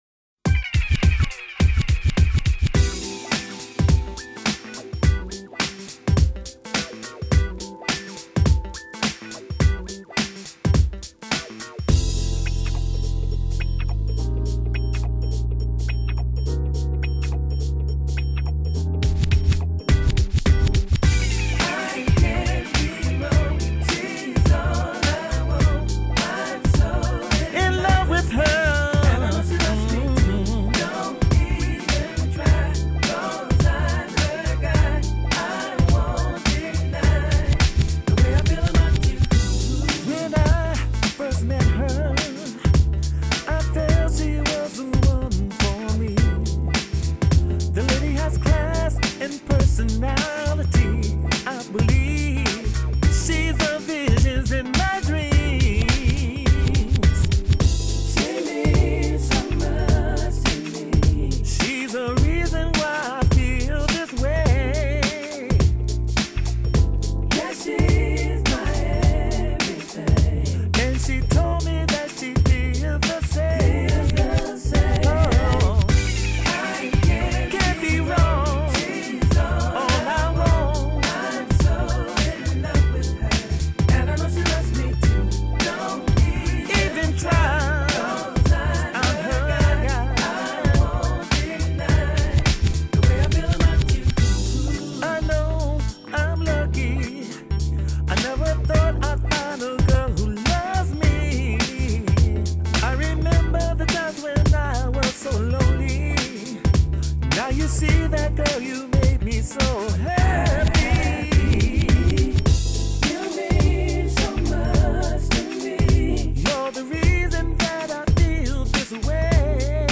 Home > Music > Rnb > Bright > Smooth > Medium